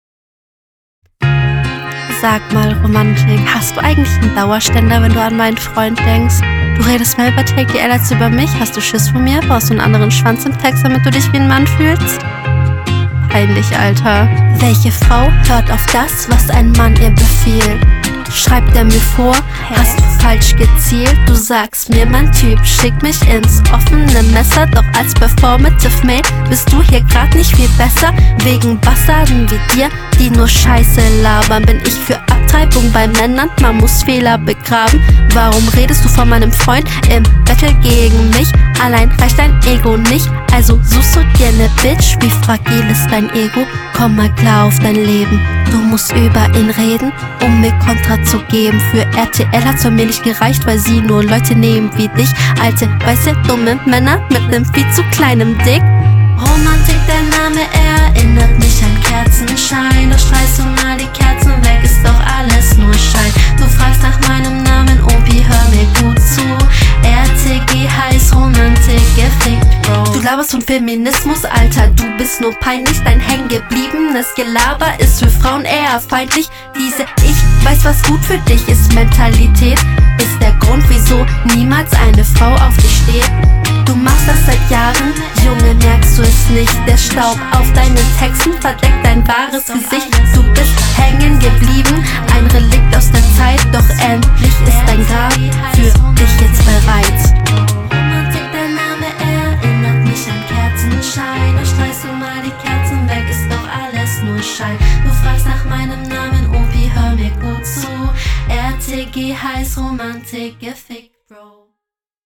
Ich finde die Runde gut gerappt und auch vom Stil her sehr cool.
Nett gekontert, die Hook ist auch nice, der Flow ist noch ausbaufähig, aber insgesamt gut …